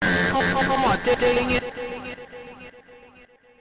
aww dang it meme sound effect
aww-dang-it-meme-sound-effect.mp3